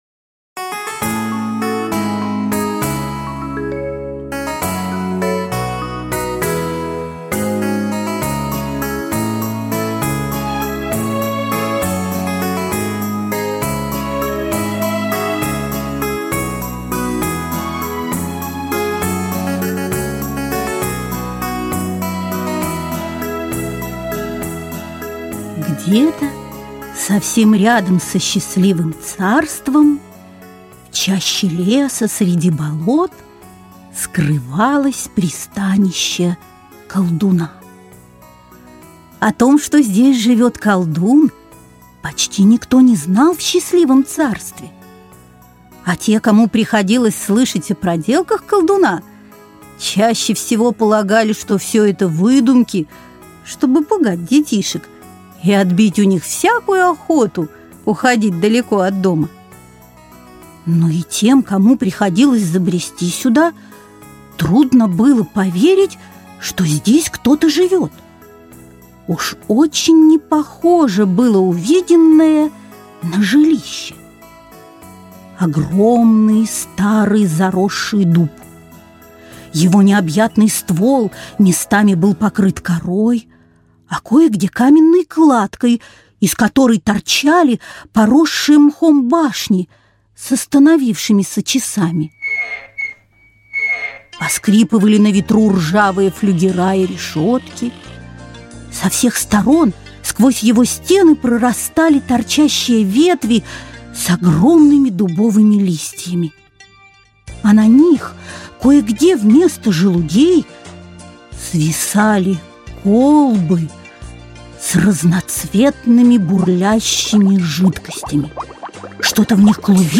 Аудиокнига Счастливое царство | Библиотека аудиокниг
Прослушать и бесплатно скачать фрагмент аудиокниги